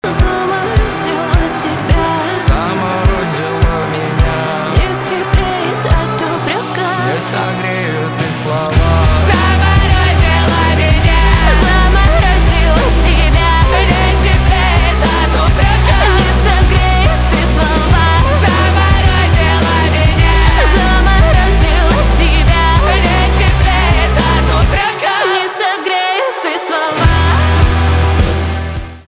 альтернатива